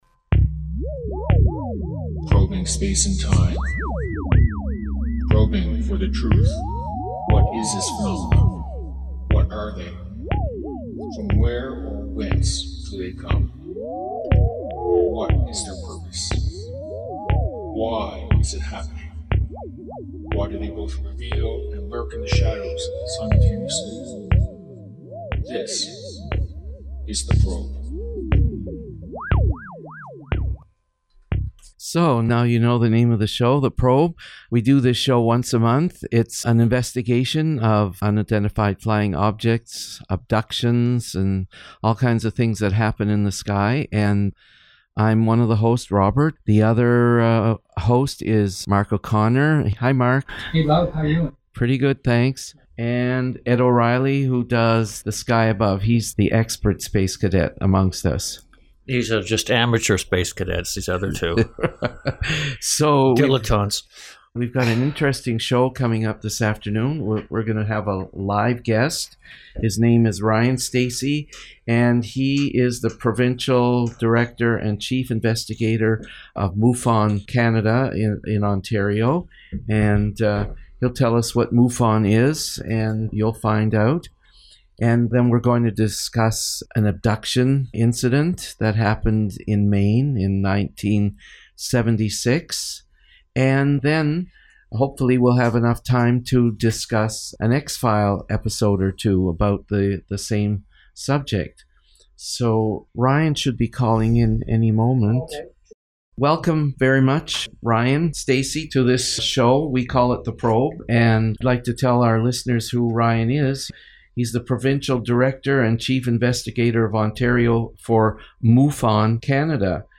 UFO Talk Show